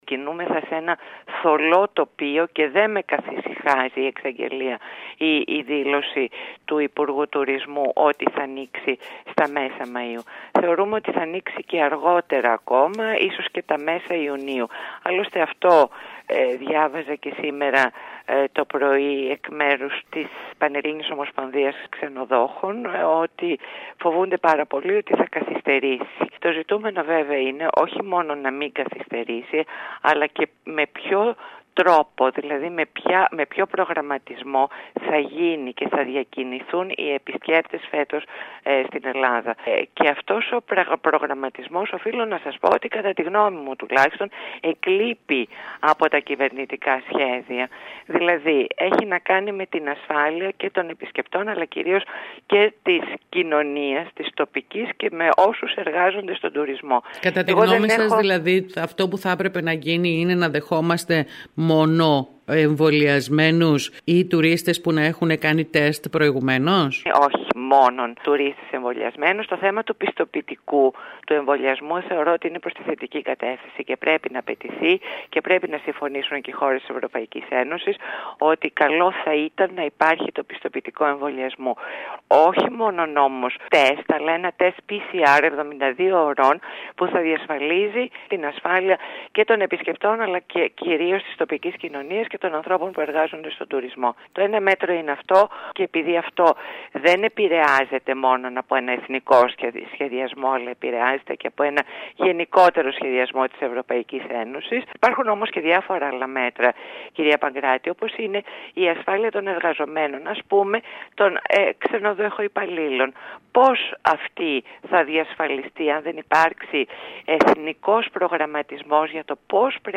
μέλος της Κ.Ε. του ΚΙΝΑΛ μιλώντας σήμερα στην ΕΡΑ Κέρκυρας.